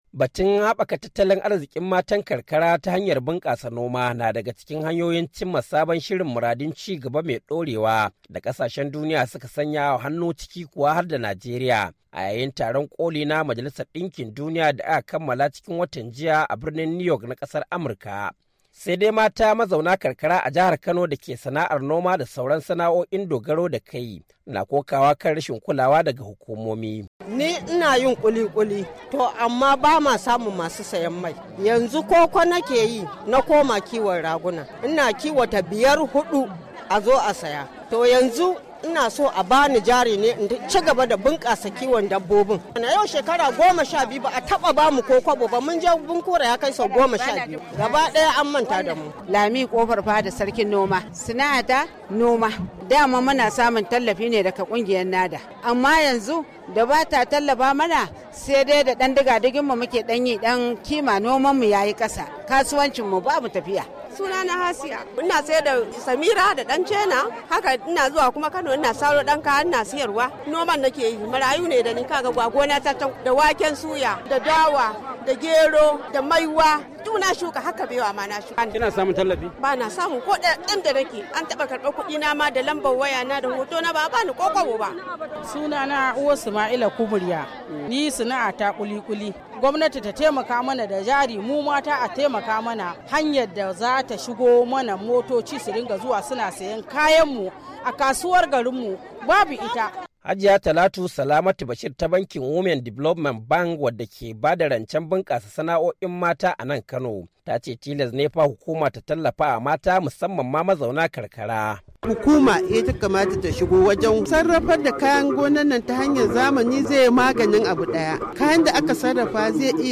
Rahoton Ranar Matan Karkara ta Duniya - 3'29"